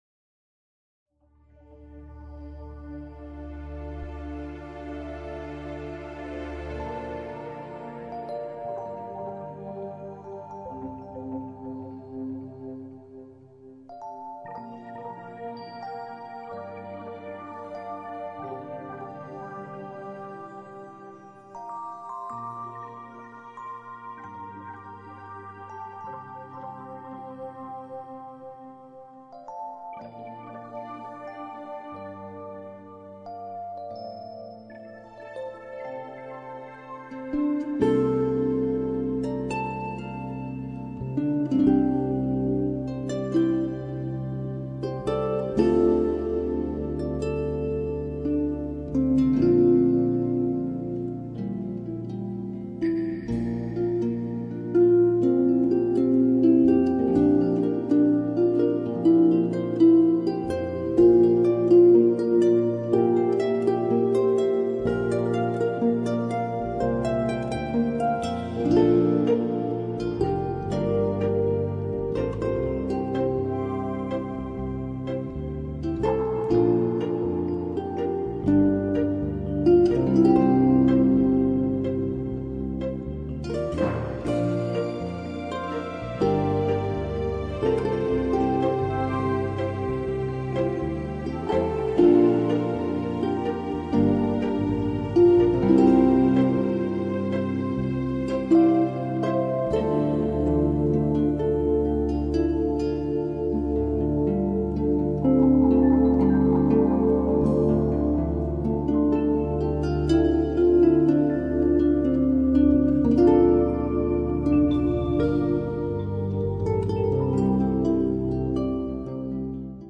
☆活泼的节奏、特出的主旋律、平易近人的音乐语汇， 有如不受拘束的云与风， 自由自在的飞翔於天空和原野。